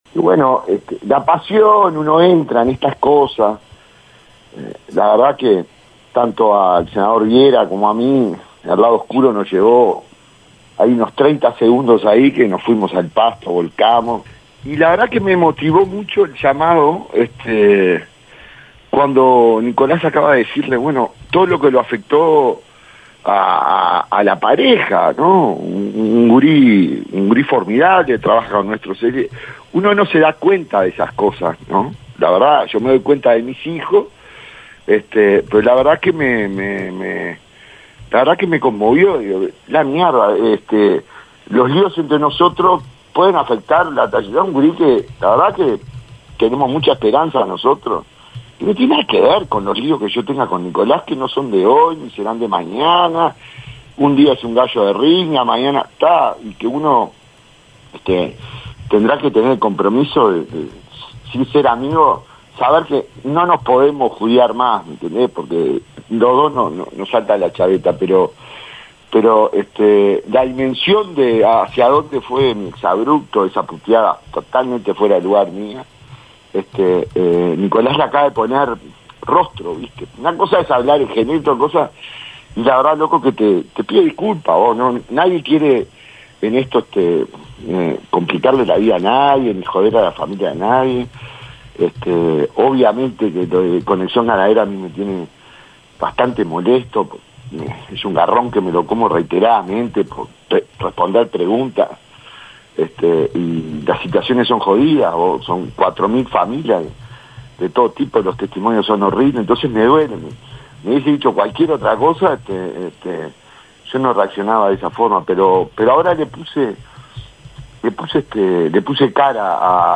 Por este motivo, el senador nacionalista decidió irrumpir en vivo en la entrevista consignada por Radio Universal a Nicolás Viera para pedir disculpas.